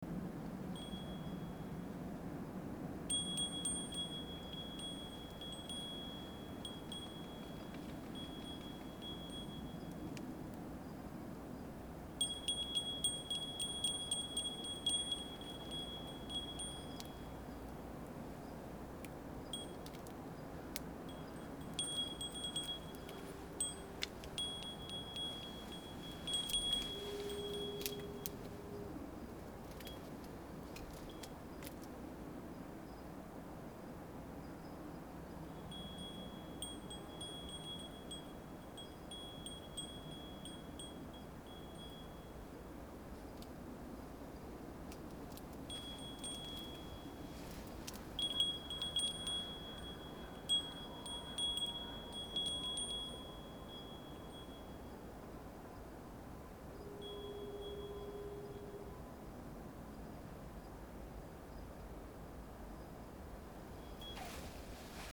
Listen to the audio I recorded in the back.